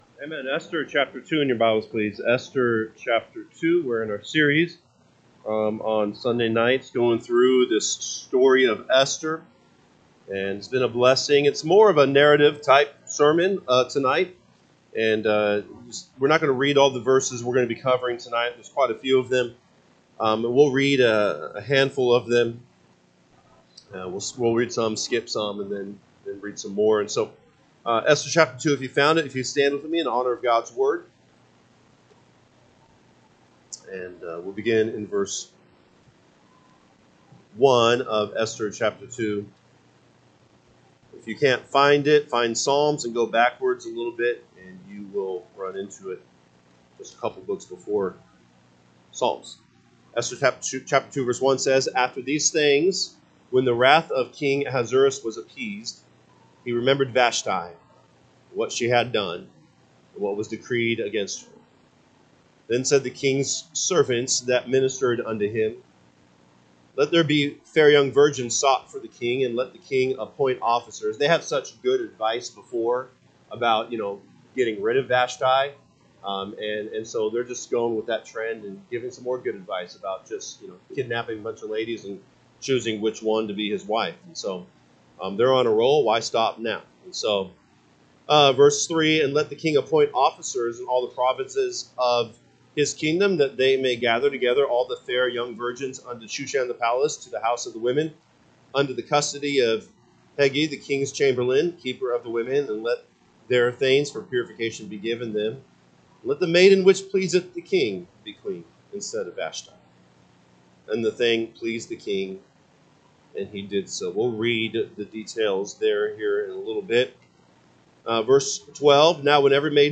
June 1, 2025 pm Service Esther 2:1-4 (KJB) 2 After these things, when the wrath of king Ahasuerus was appeased, he remembered Vashti, and what she had done, and what was decreed against her. 2…
Sunday PM Message